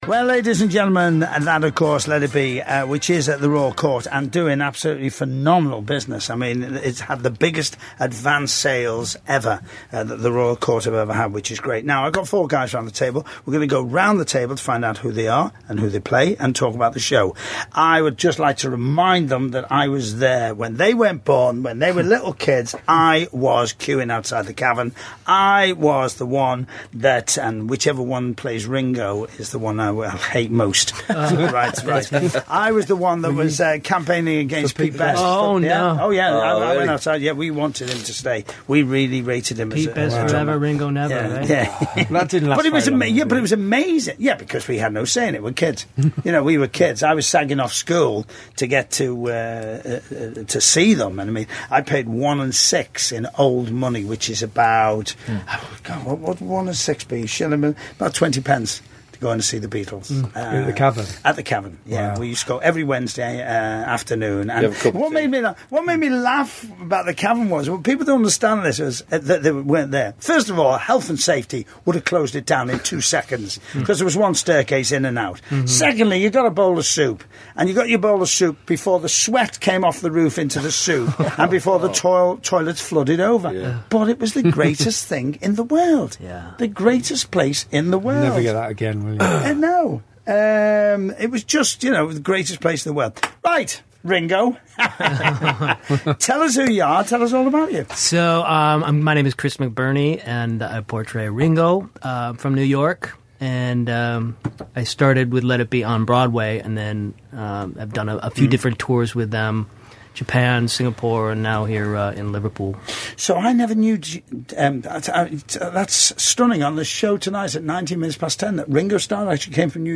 the cast of Let It Be chatting